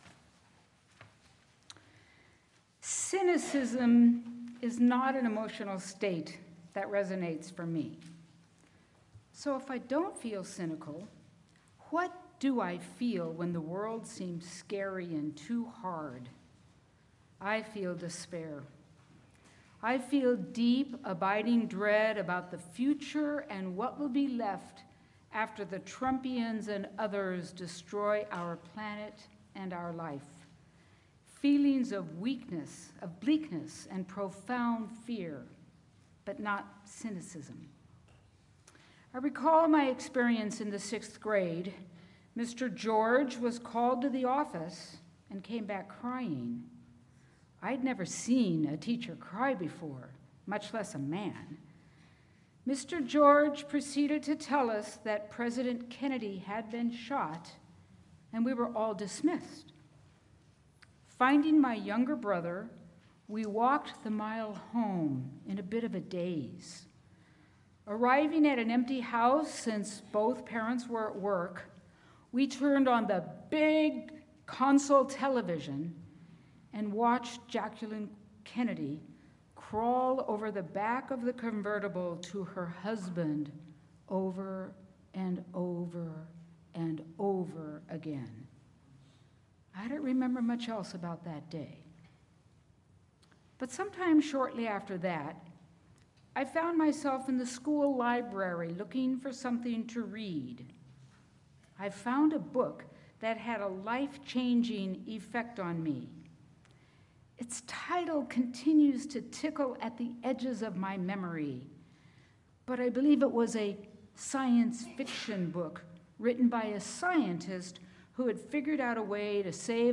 Sermon-Anitodote-to-Cynicism.mp3